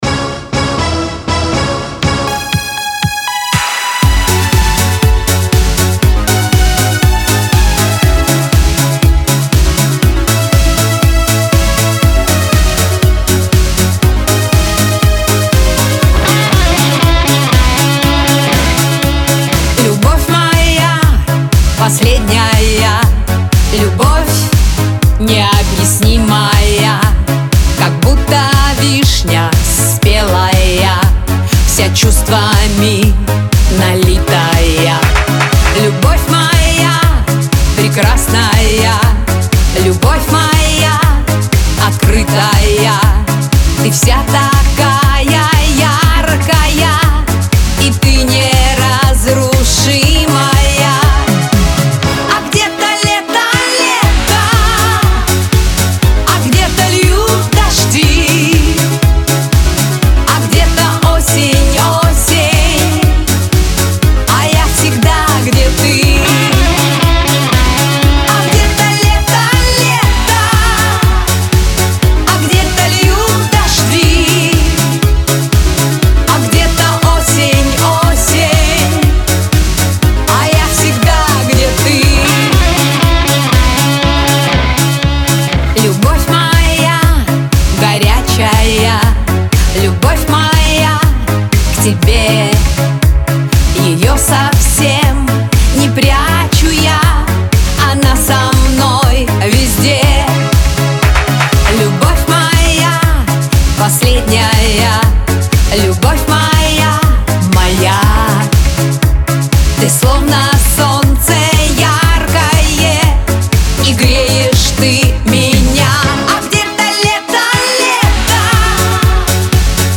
pop , Лирика